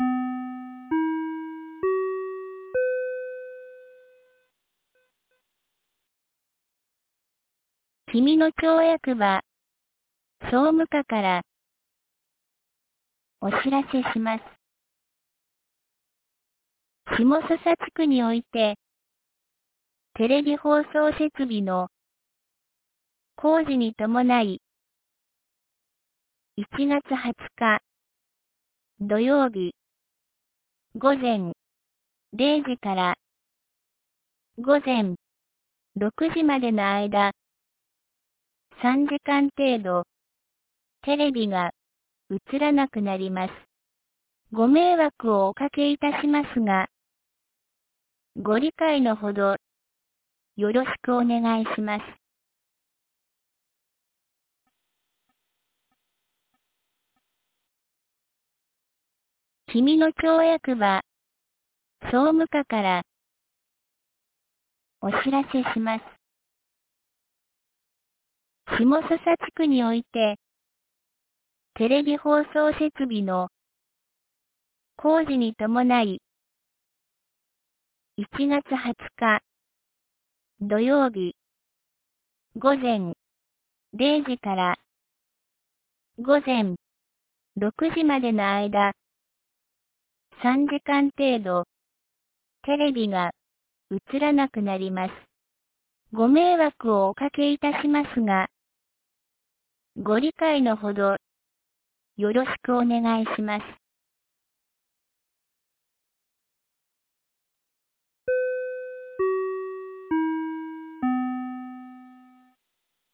2024年01月18日 12時31分に、紀美野町より東野上地区へ放送がありました。
放送音声